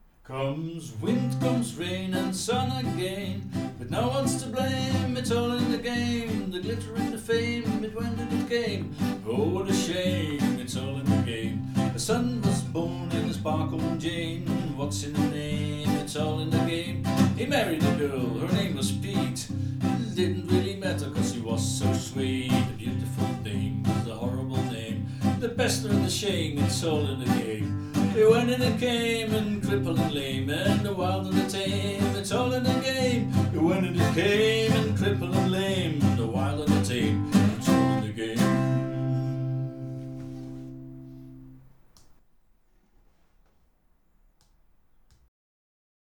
Since then they perform as a trio.